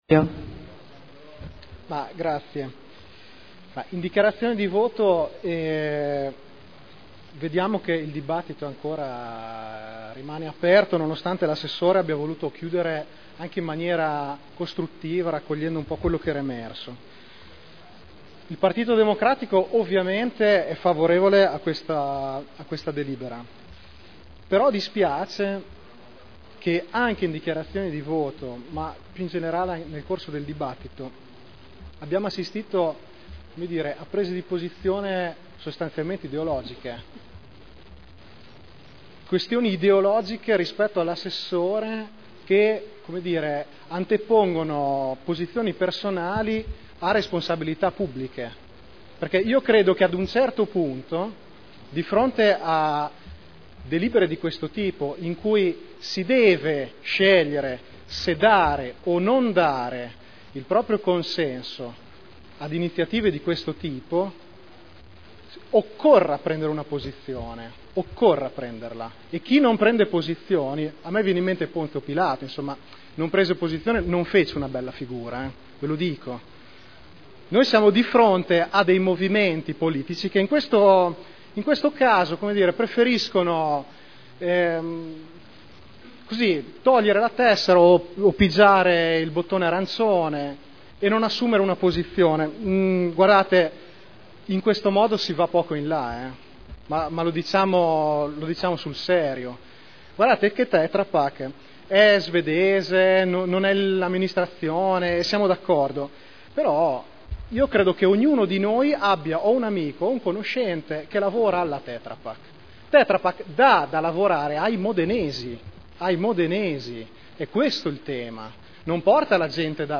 Seduta del 13/12/2010 Deliberazione: Variante al P.O.C.-RUE – AREA in via Emilia Ovest Z.E. 1481-1502 – Adozione Dichiarazioni di voto